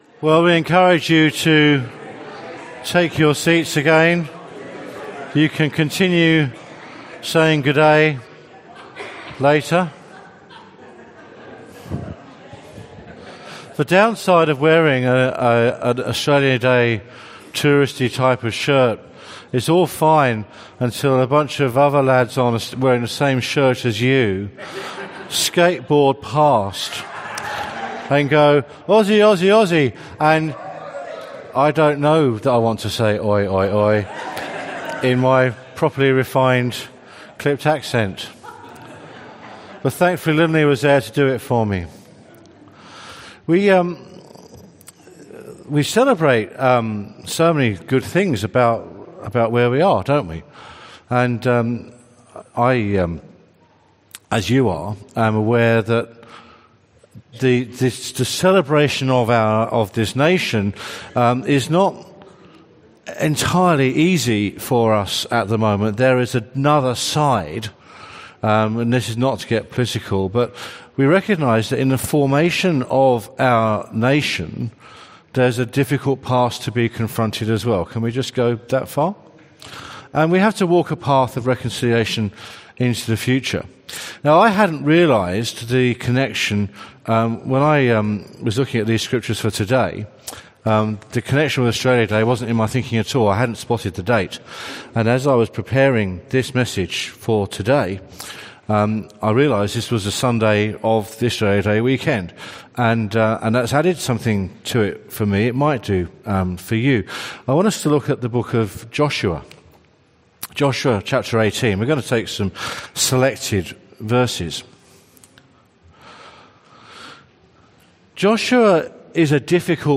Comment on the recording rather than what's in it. Bible reading and sermon from 10AM meeting on 28/01/2018 at Newcastle Worship & Community Centre. The Bible reading was taken from Joshua 18:1-6, 19:1-9, 40-48.